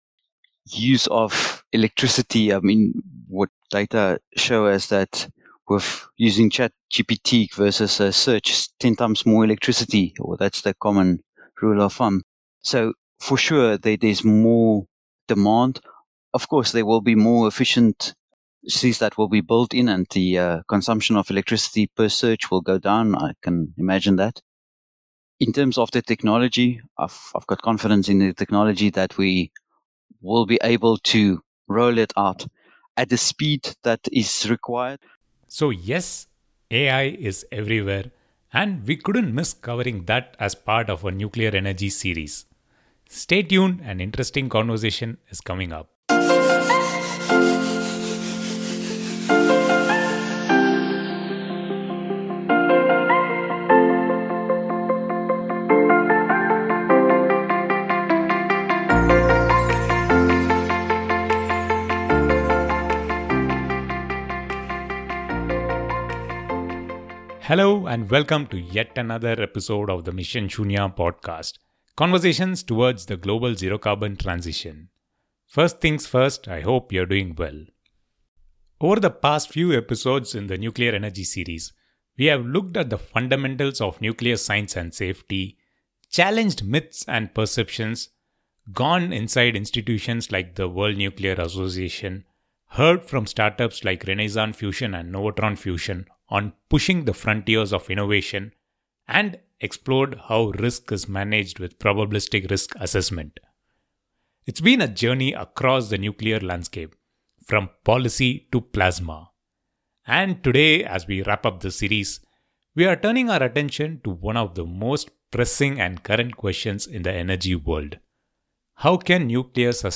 conversations towards the global zero carbon transition